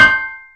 axe_ceramic.wav